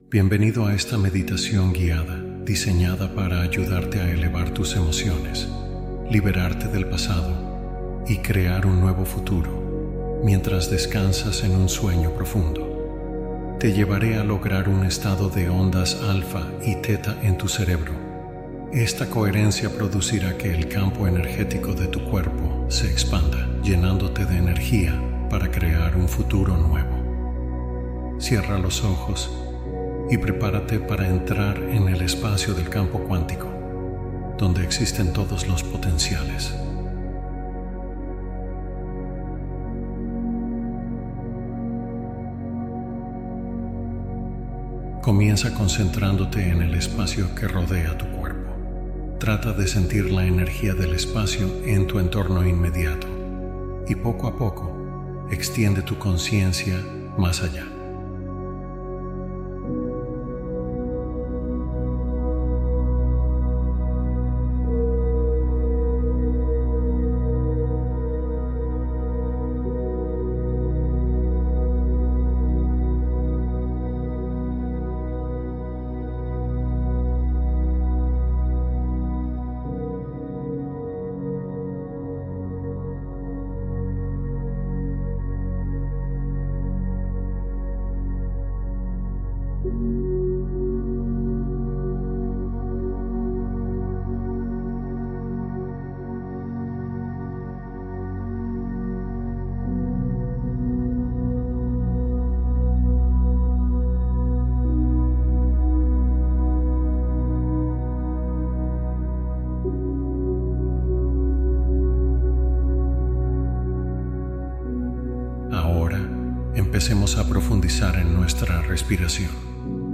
Visualización meditativa para imaginar un nuevo futuro